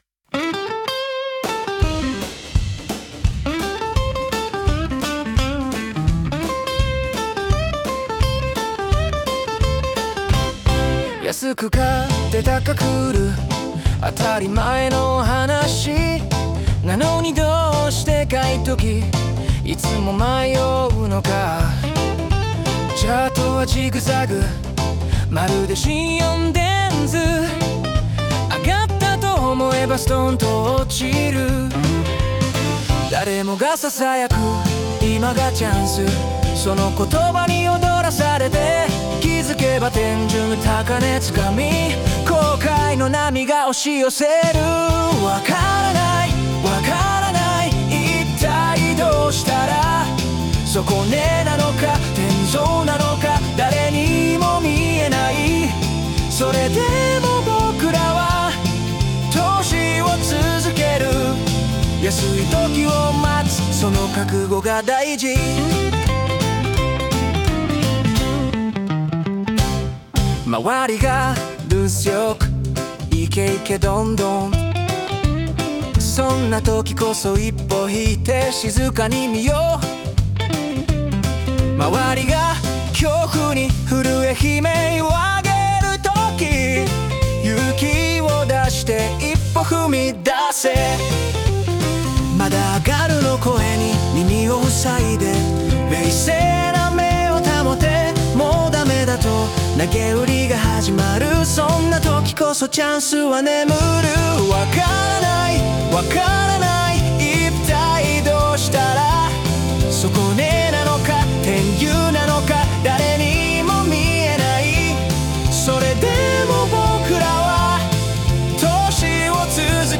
暇に任せてsunoAIで投資の曲を作ってみました。
個人的には1曲目の方がメロディーは好きなんですが、歌詞の読み間違えが気になっちゃいますね💦￼￼